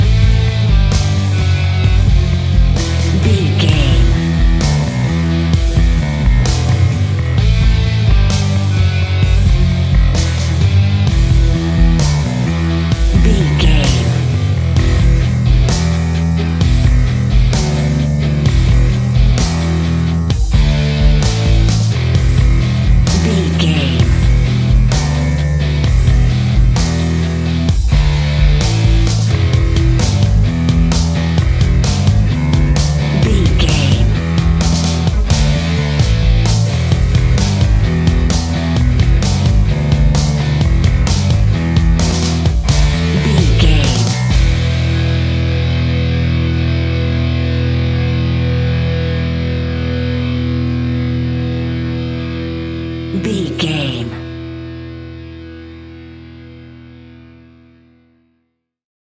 Epic / Action
Fast paced
Aeolian/Minor
hard rock
blues rock
distortion
rock guitars
Rock Bass
Rock Drums
heavy drums
distorted guitars
hammond organ